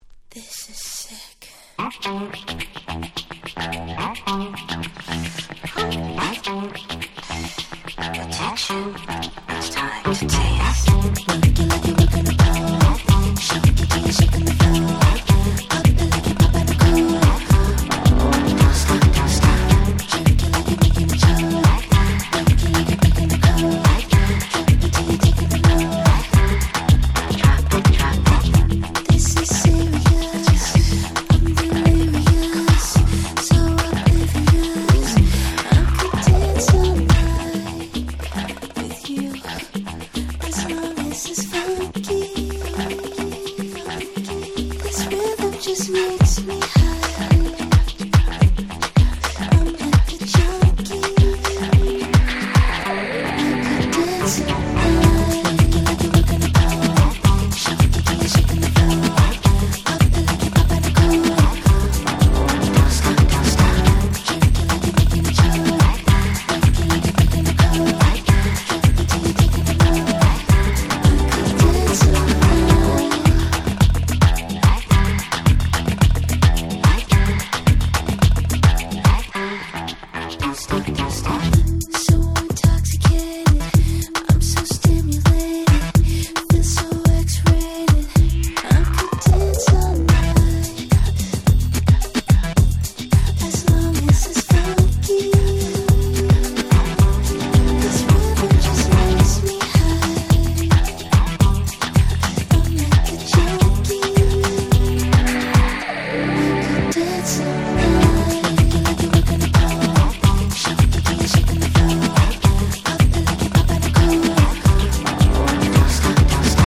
04' Smash Hit R&B.